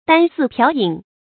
箪食瓢饮 dān shí piáo yǐn
箪食瓢饮发音